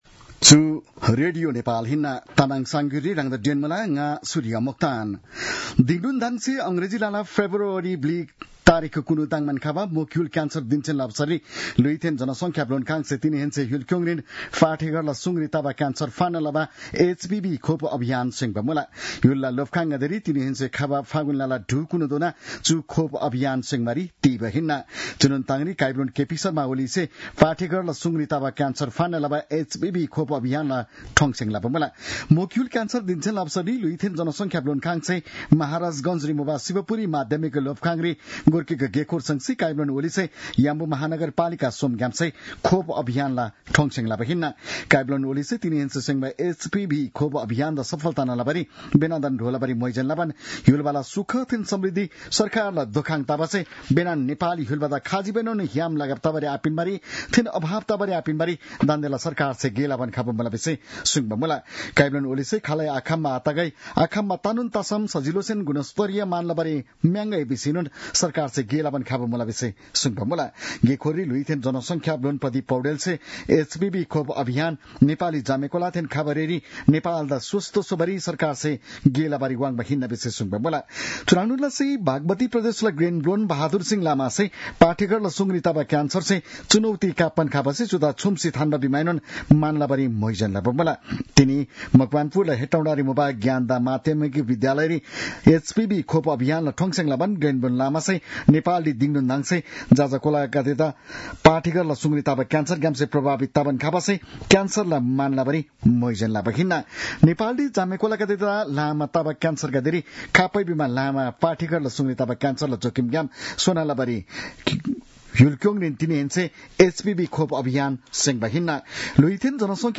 तामाङ भाषाको समाचार : २३ माघ , २०८१